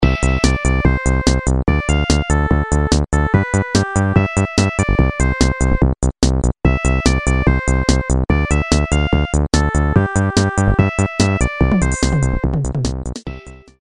Nokia полифония. Мультики